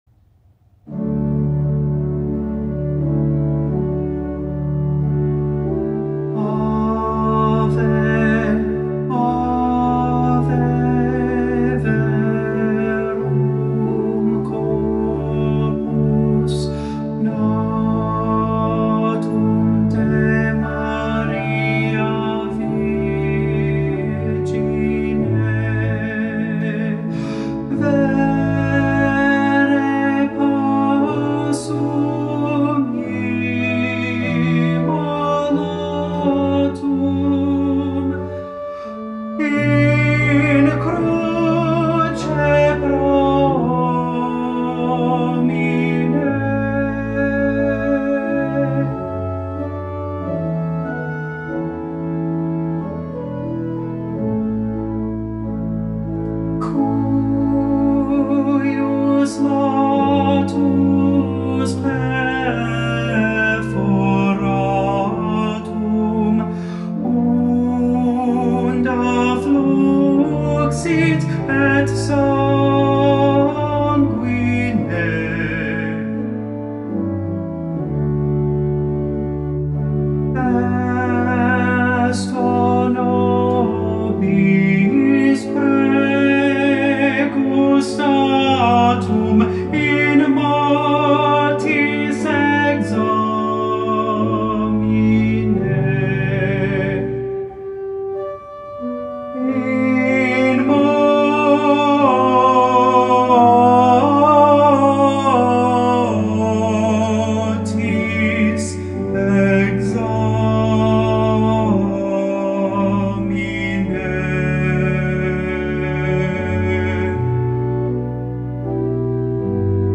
MP3 versions chantées
Tenor
Ave Verum Corpus Mozart Tenor Practice Mp 3